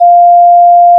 F5.aiff